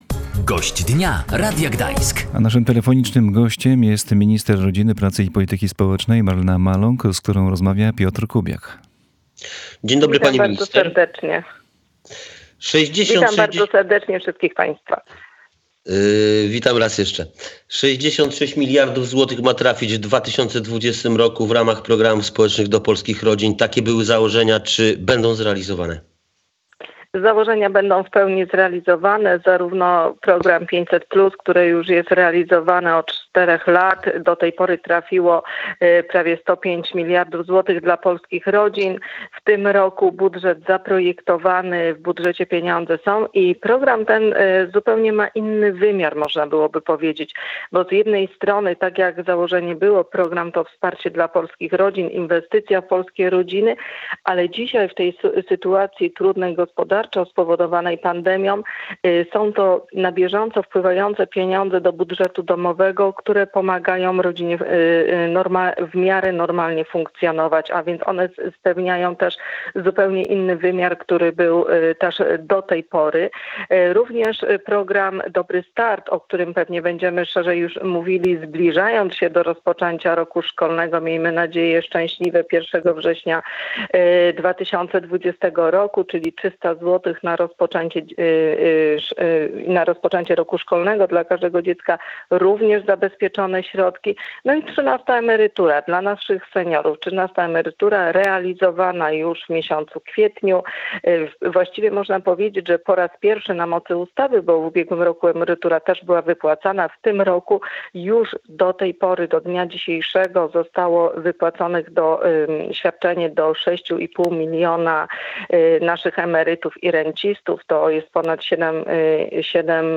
minister rodziny, pracy i polityki społecznej.